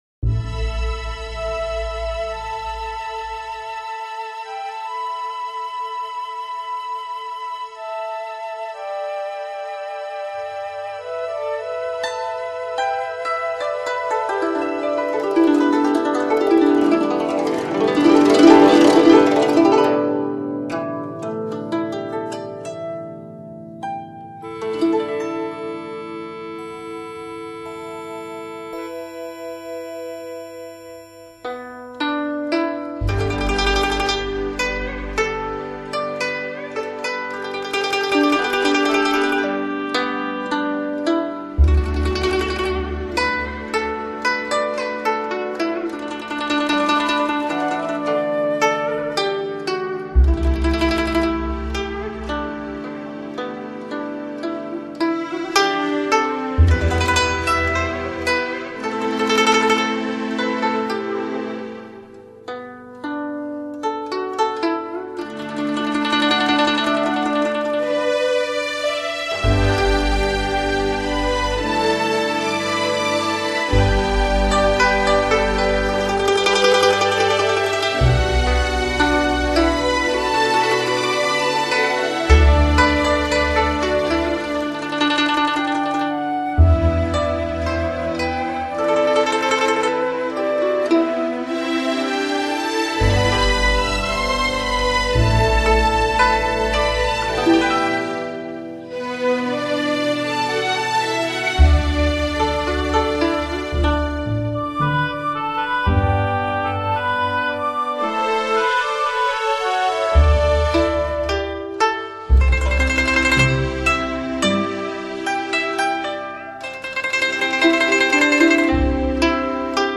二胡协奏曲
古筝与弦乐